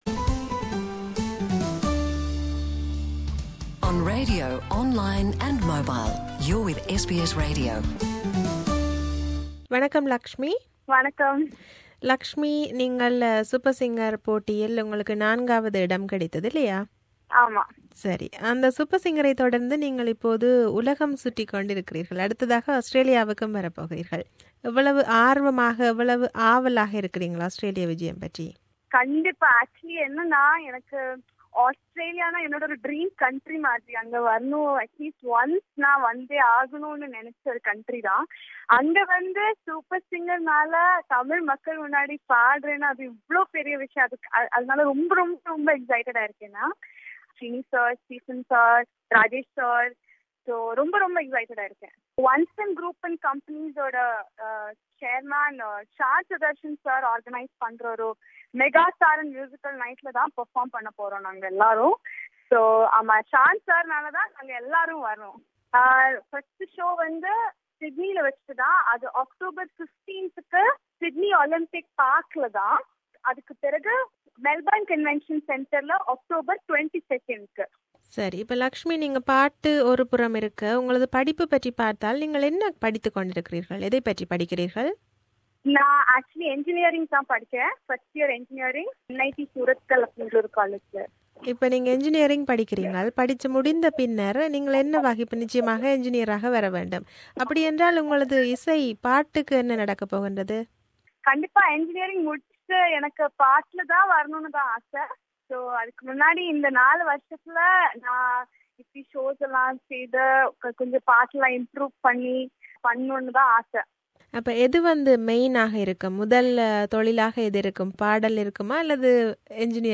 This is an interview with her.